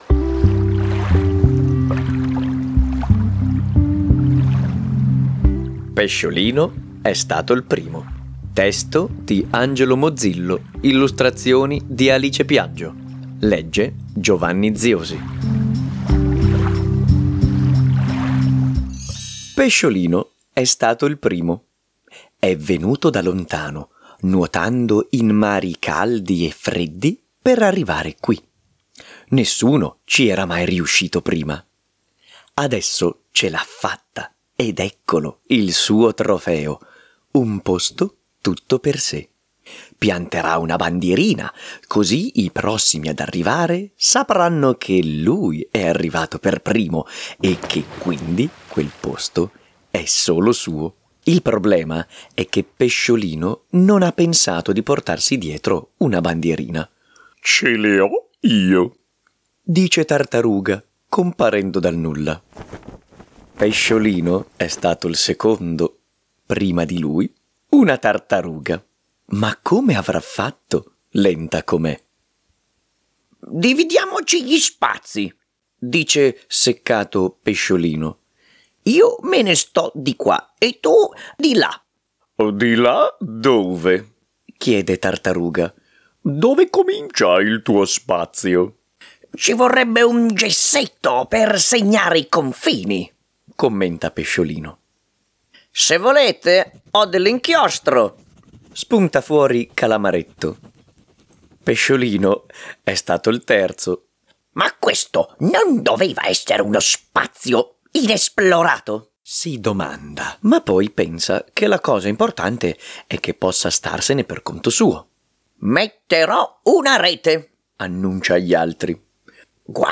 senza tappeto sonoro